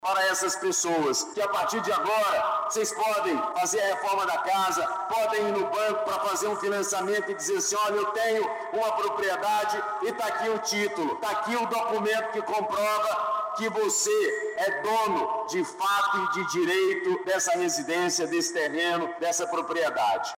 Durante a entrega dos títulos, o governador Wilson Lima destacou que, com os documentos em mãos, os proprietários terão acesso à vários benefícios.